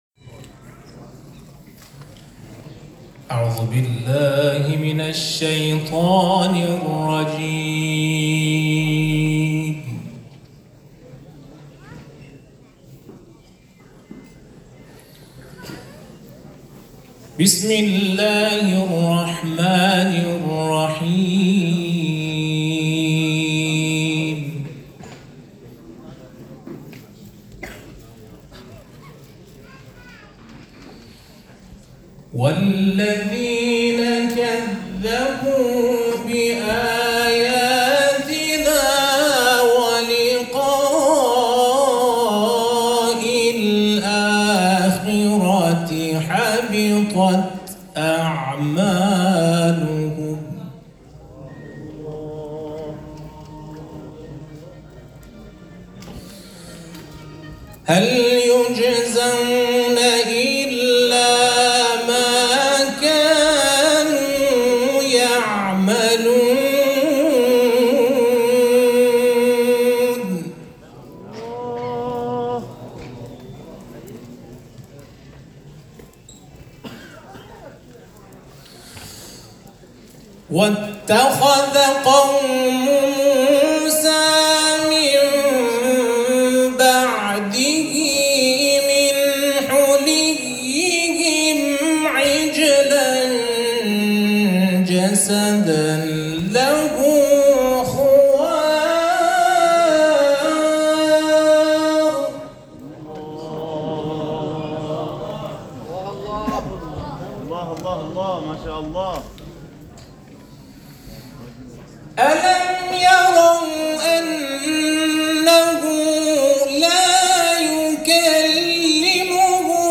قاری شرکت‌کننده در چهل و پنجمین دوره مسابقات سراسری قرآن